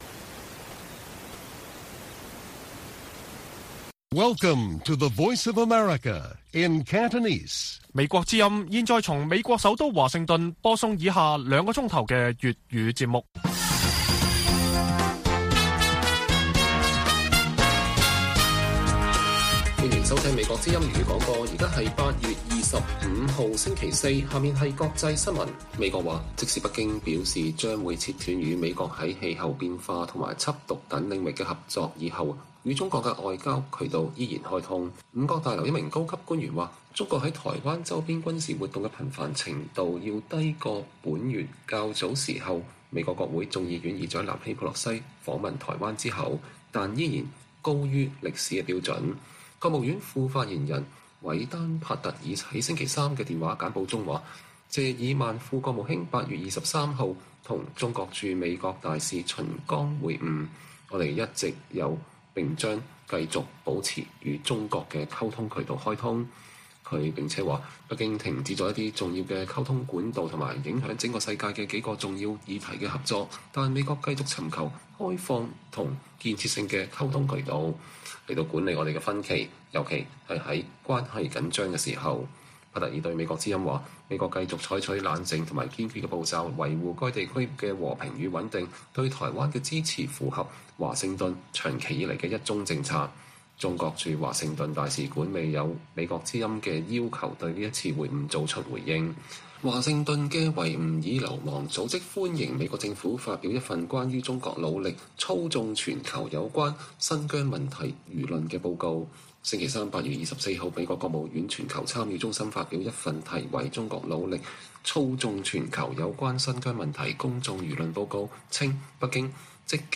粵語新聞 晚上9-10點: 美國在不斷增加的緊張關係中尋求與中國的建設性溝通